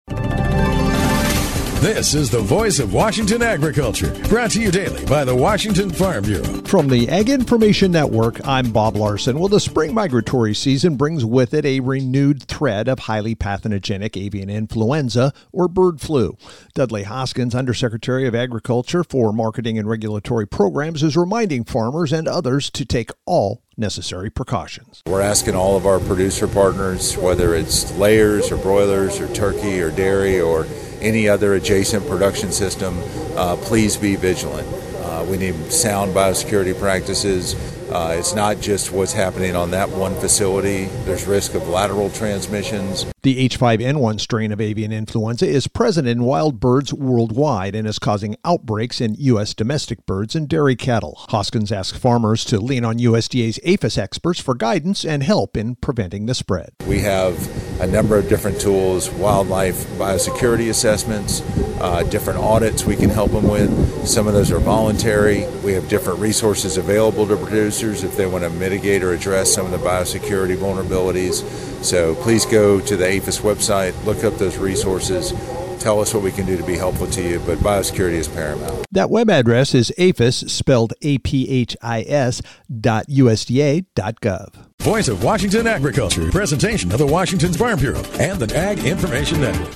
Wednesday Mar 25th, 2026 37 Views Washington State Farm Bureau Report